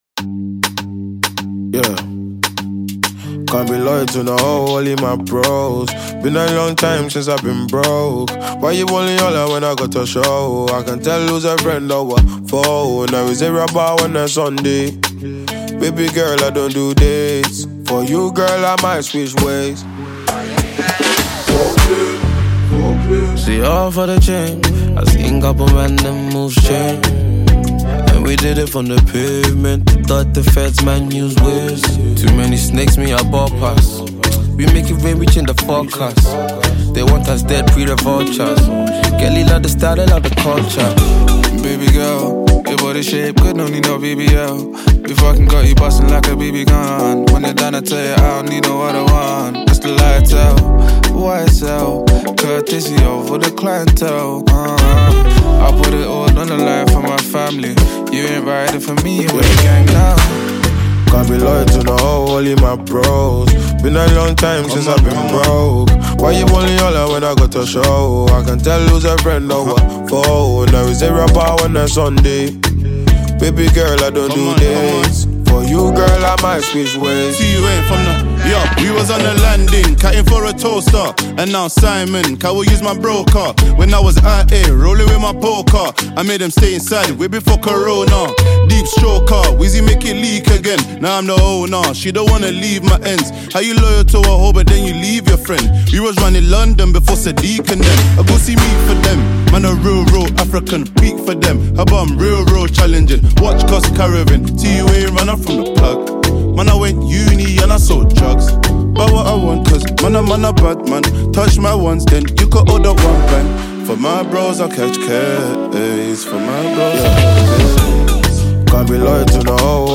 United Kingdom rapper and singer
East London rap collective